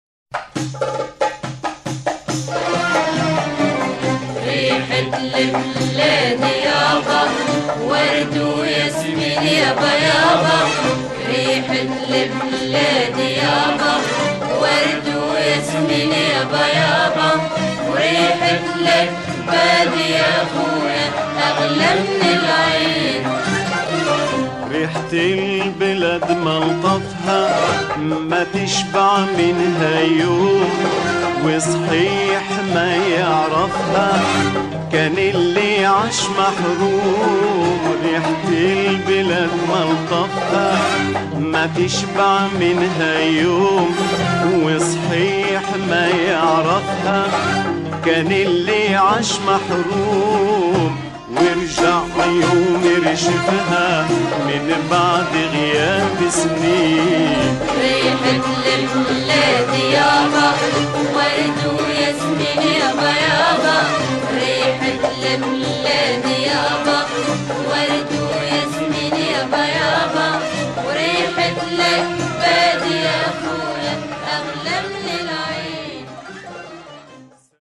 arabe_darbouka_violons_voix.mp3